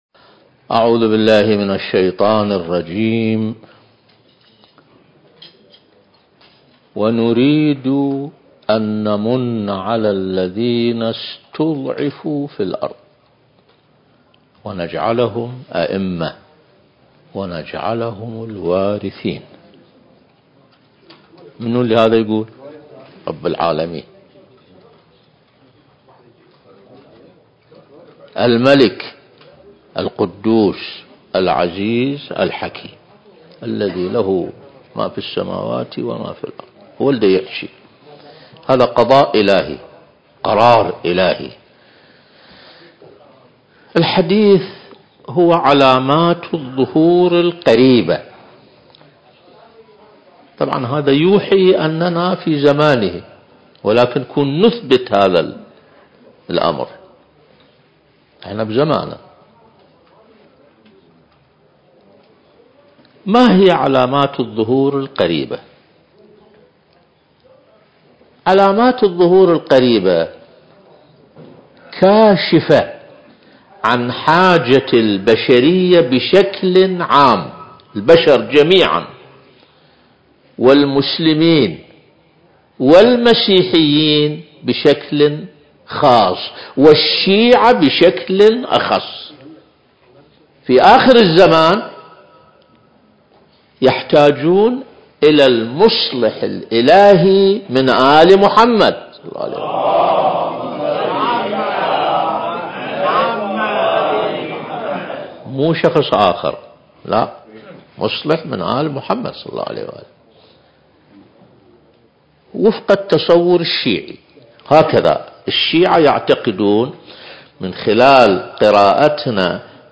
المكان: جامع وحسينية أهل البيت (عليهم السلام)/ بغداد مركز فجر عاشوراء الثقافي - العتبة الحسينية المقدسة التاريخ: 2023